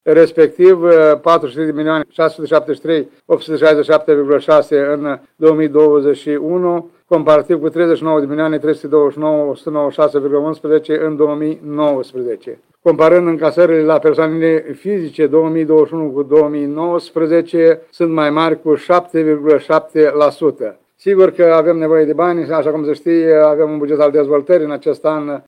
Primarul ION LUNGU a declarat că o comparație cu anul trecut nu este relevantă, deoarece atunci a debutat pandemia de coronavirus, iar perioada de acordare a bonusului de 10 % a fost prelungită.